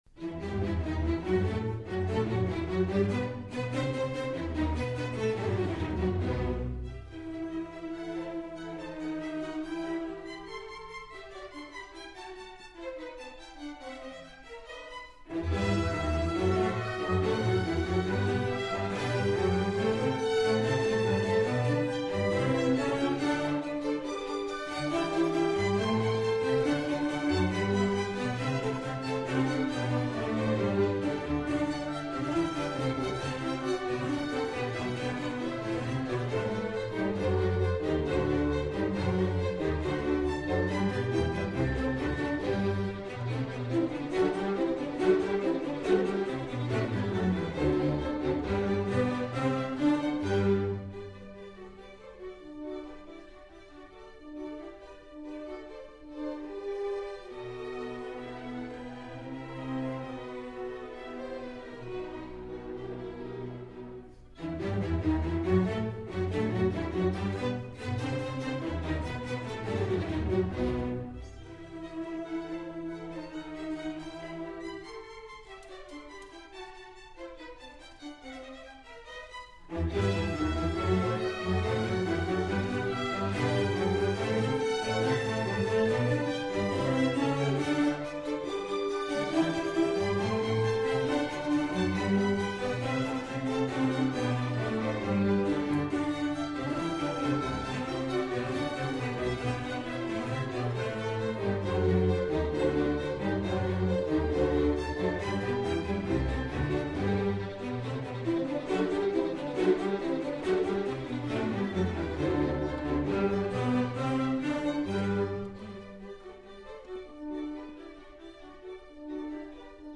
This concert took place in December 2011.
haydns-symphony-no-44.mp3